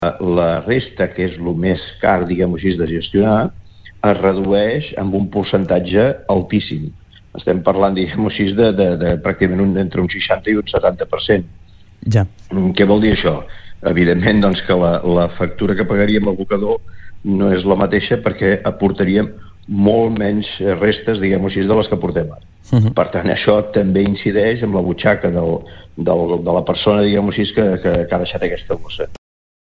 El Consell Comarcal considera que és “urgent” que hi hagi una planta de triatge al Baix Empordà. Ens ho va explicar ahir al Supermatí el president del Consell Comarcal, Joan Manel Loureiro.